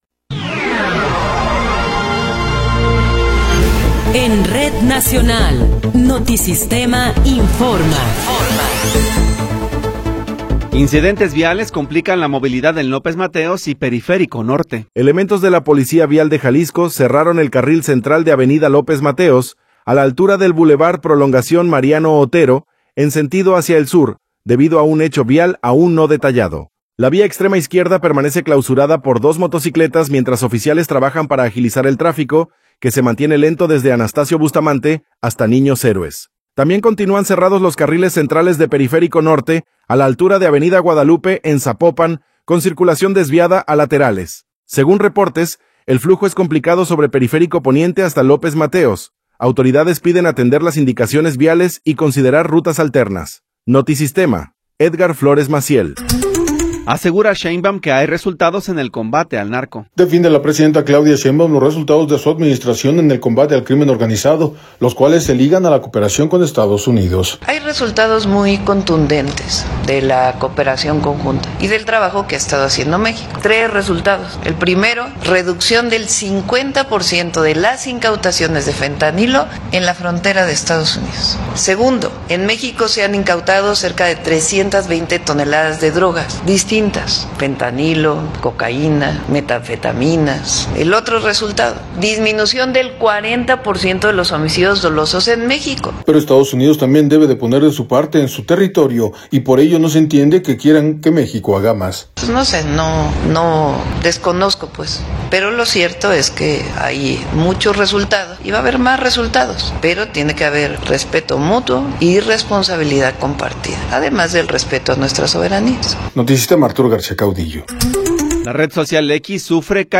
Noticiero 11 hrs. – 16 de Enero de 2026